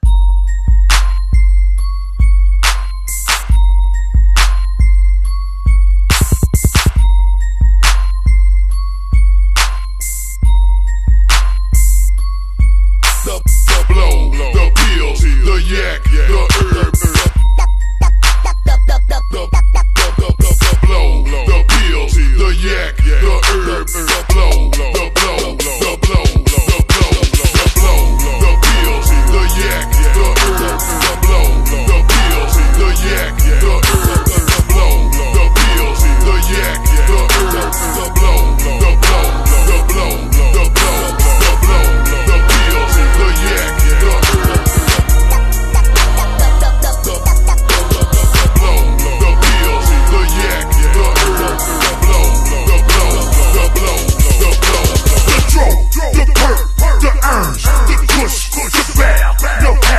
2025 Silverado Crew Cab with 3 DD Audio 710F Subwoofers in our Custom Ported Under Seat Box. We Specialize in Loud Truck Builds!